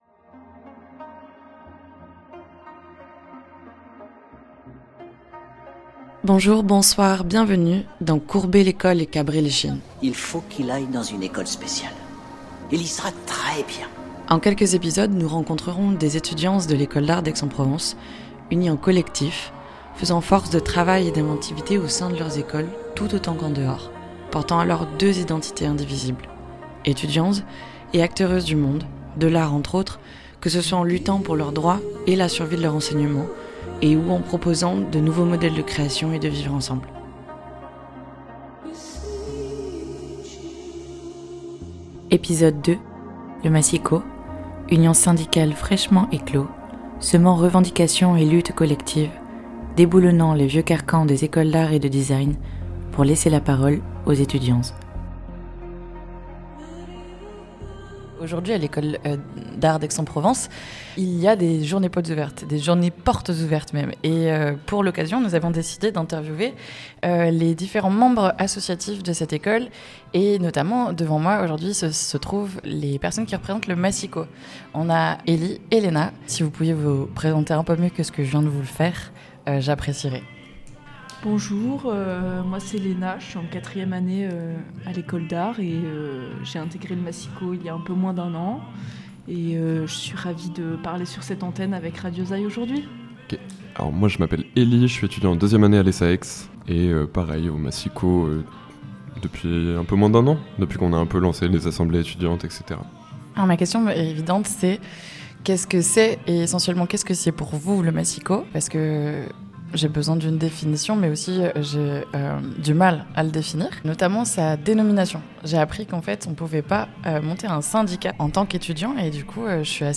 Rencontre avec les étudiantz de l'école d'Art d'Aix-en-Provence : création d'un collectif d'artiste dès le 1e cycle, être en tête de cortège en militant pour la survie de nos écoles, éduquer et soigner, créer des environnements sécurisants et fait d'écoute au sein de nos structures d'enseignement, continuer à se retrouver et vivre ensemble après le COVID...etc.